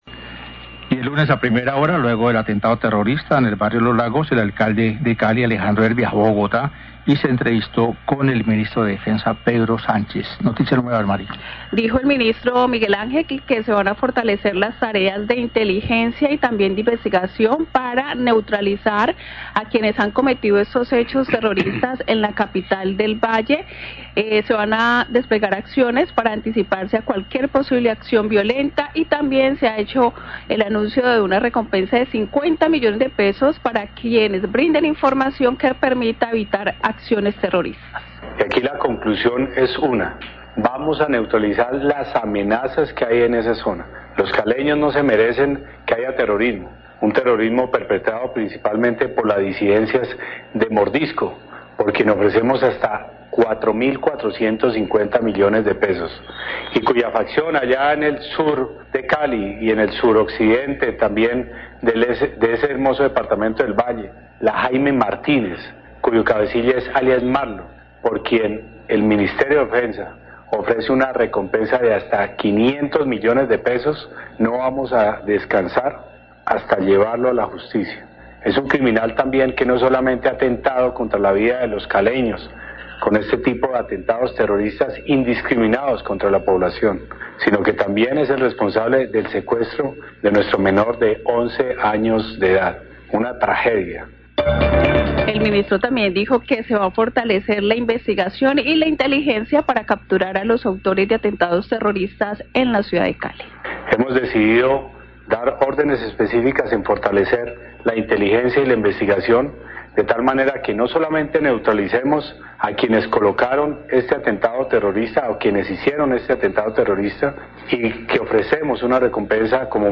Radio
NOTICIERO RELÁMPAGO